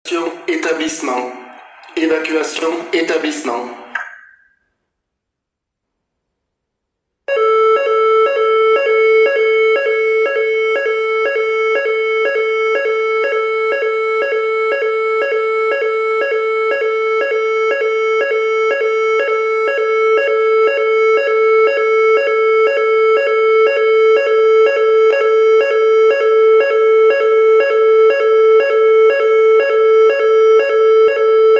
Signaux d'alarmes
Vous voudrez bien prendre connaissance des signaux sonores d’alarmes :
1) évacuation incendie